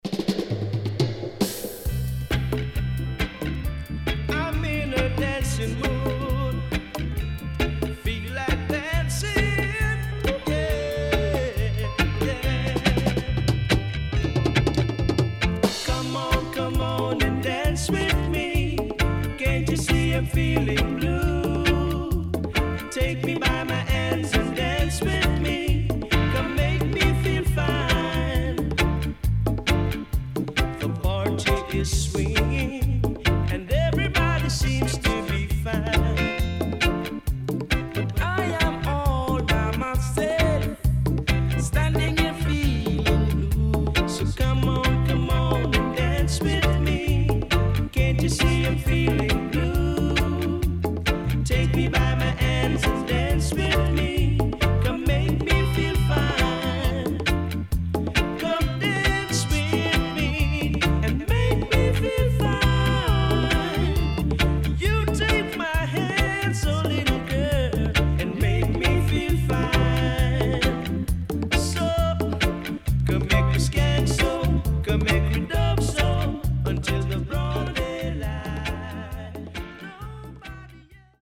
HOME > DISCO45 [VINTAGE]  >  KILLER & DEEP
SIDE A:少しチリノイズ入りますが良好です。